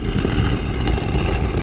scrape0f.wav